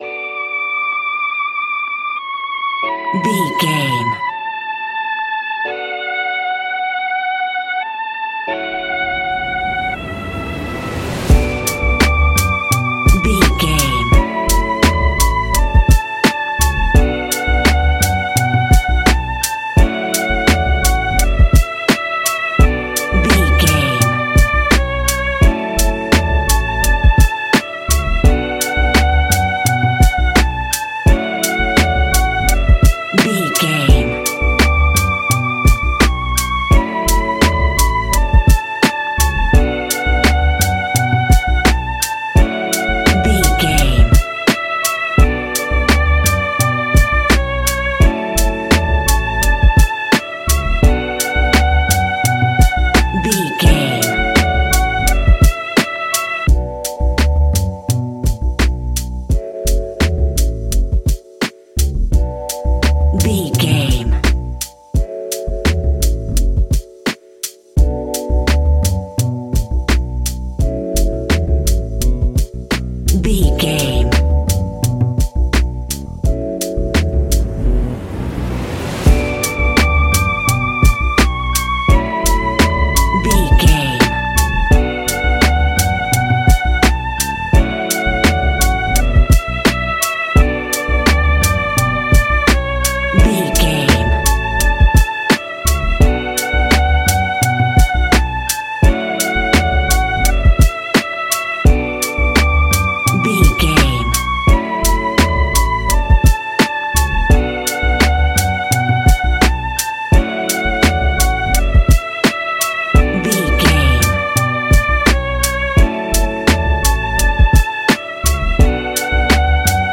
Ionian/Major
B♭
chilled
laid back
Lounge
sparse
new age
chilled electronica
ambient
atmospheric